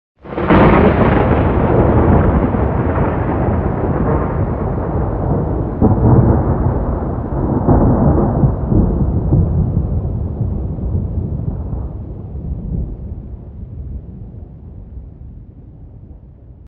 Звуки молнии
На этой странице собраны разнообразные звуки молнии и грома – от резких электрических разрядов до глубоких раскатов после удара.